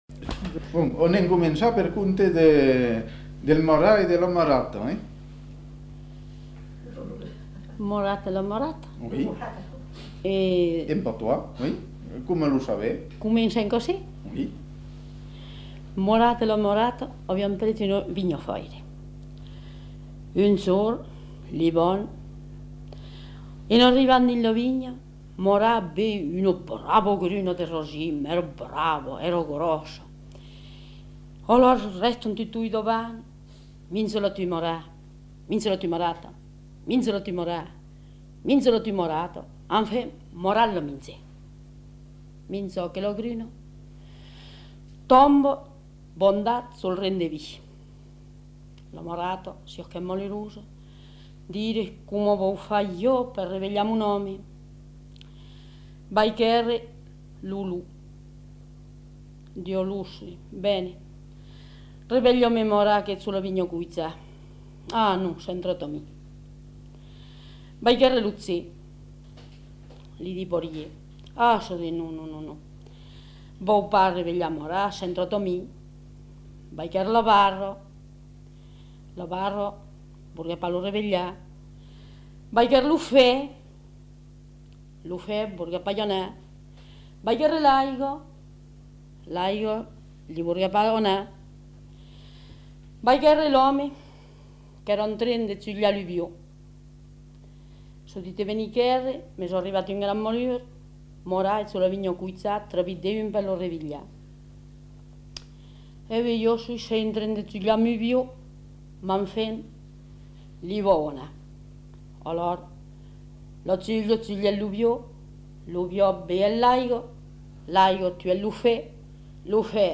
Aire culturelle : Périgord
Lieu : Castels
Genre : conte-légende-récit
Type de voix : voix de femme
Production du son : parlé